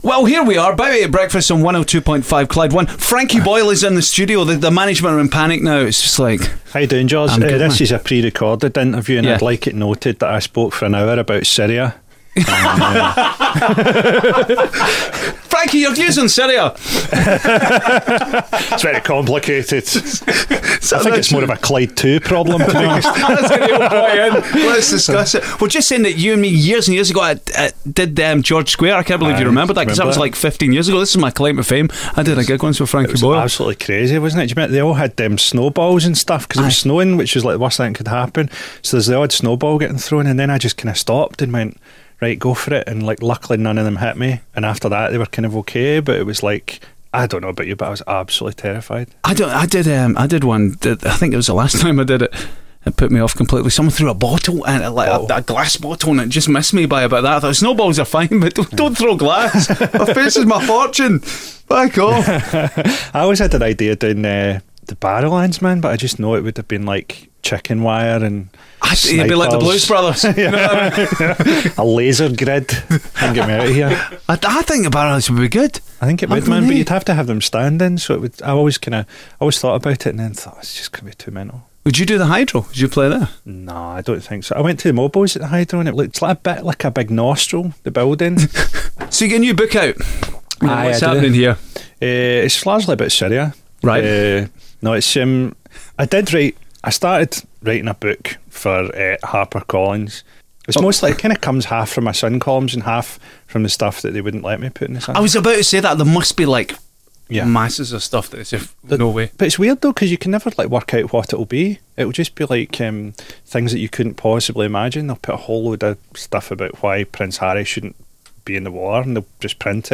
Hear what controversial comedian Frankie Boyle had to say when he popped by for a chat